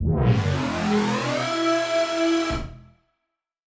Index of /musicradar/future-rave-samples/Siren-Horn Type Hits/Ramp Up